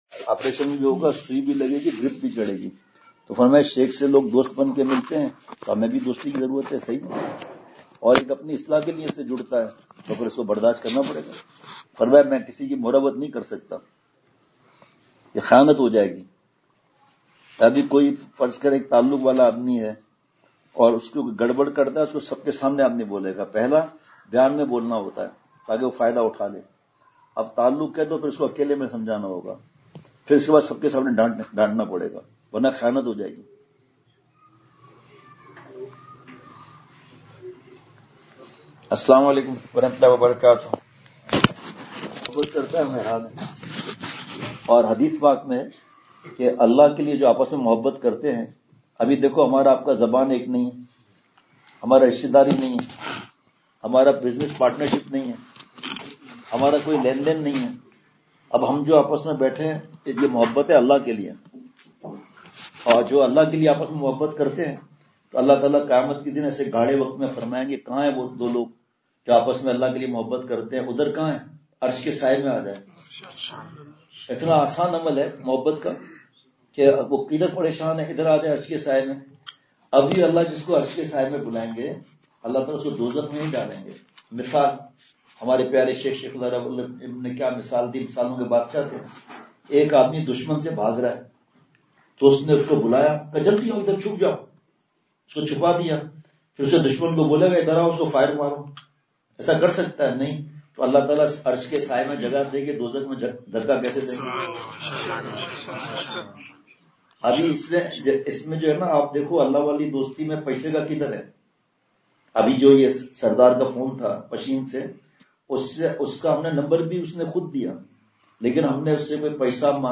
حضرت والا دامت برکاتہم العالیہ رہائش گاہ پہنچے تو میزبان کی فرمائش پر شرعی پردے سے بذریعہ اسپیکر گھر کی خواتین کے لئے بیان فرمایا۔
حضرت والا نے اشعار پڑھے ہے بری یہ گلی بڑھ گئی بے کلی۔
بیان کے آخر میں دعا ہوئی۔